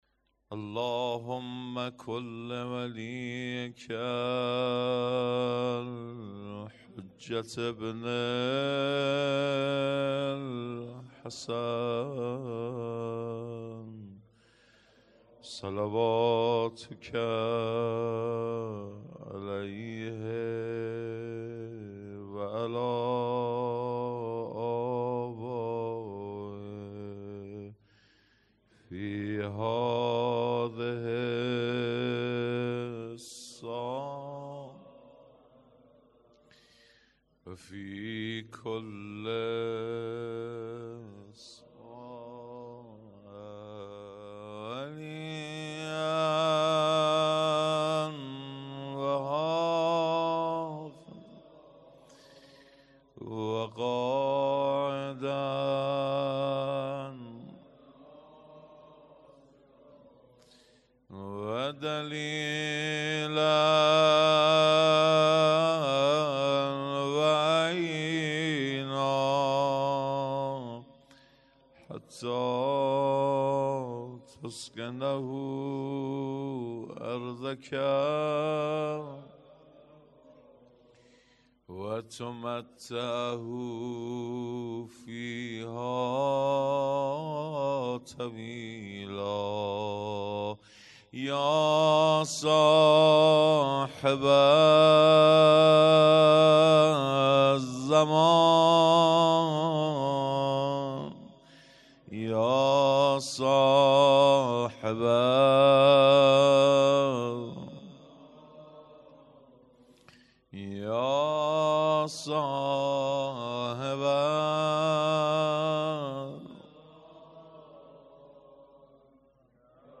مدح خوانی
شب سوم جشن ولادت امام زمان عجل الله تعالی فرجه الشریف ۱۴۰۲